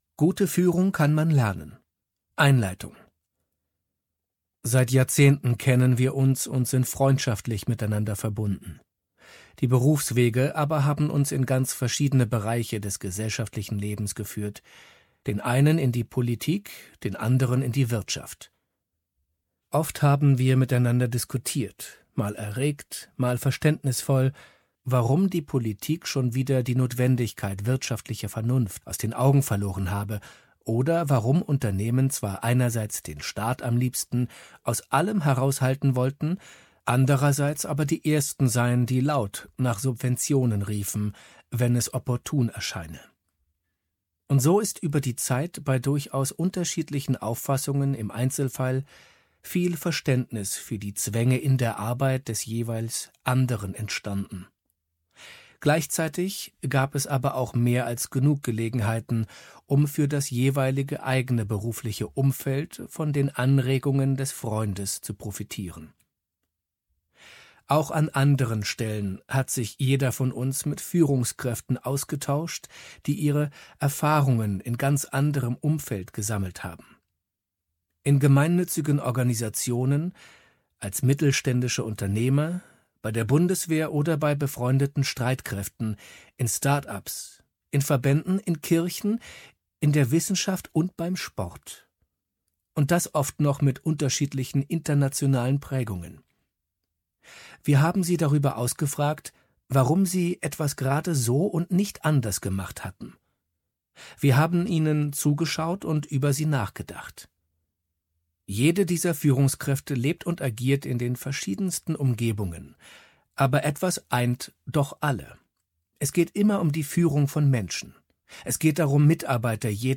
Hörbuch ruhig und ansprechend um." - ekz 18/2021